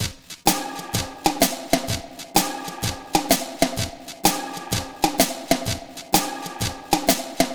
Swingerz Drumz Wet.wav